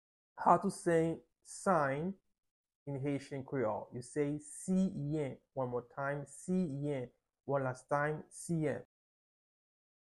Pronunciation:
16.How-to-say-Sign-in-Haitian-Creole-–-Siyen-with-pronunciation.mp3